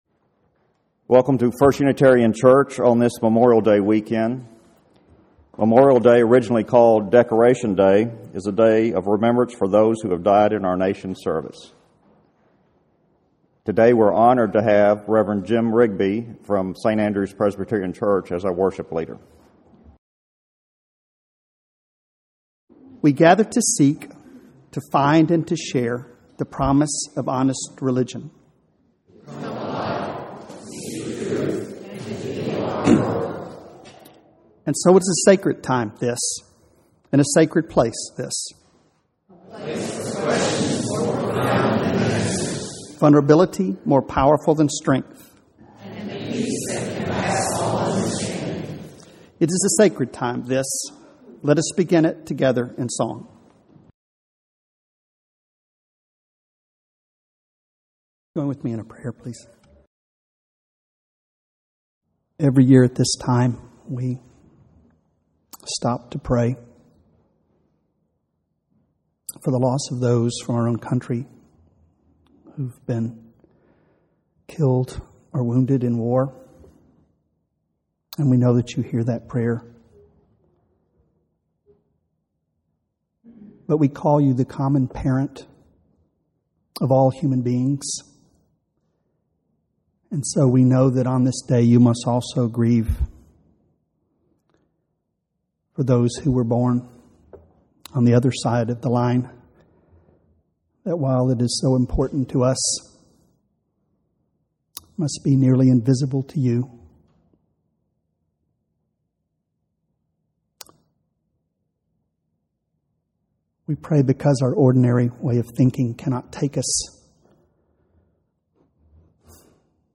2009 Text of this sermon is not available but you can listen to the sermon by clicking the play button.